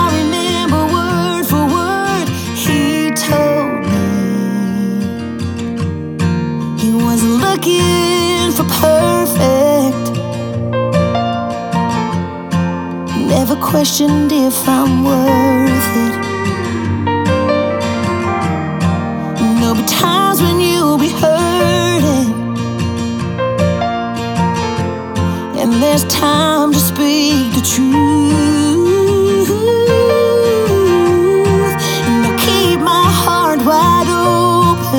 Country
Жанр: Кантри